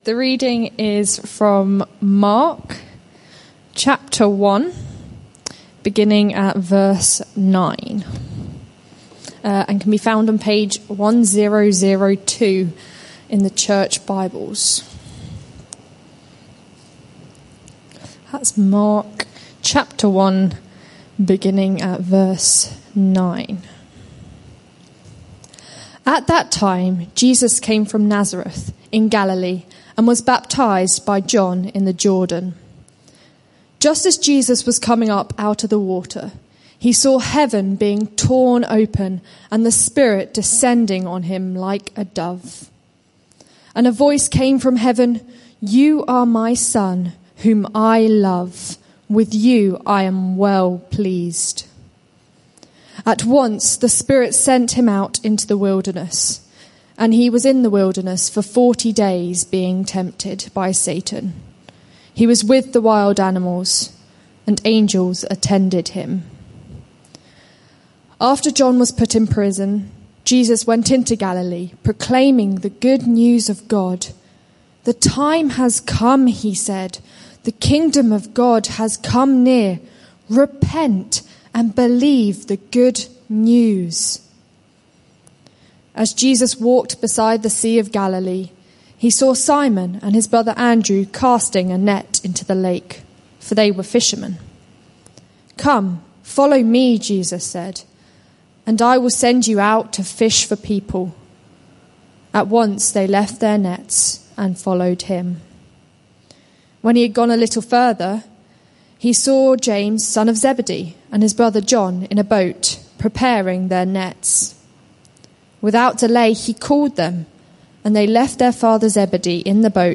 This sermon is part of a series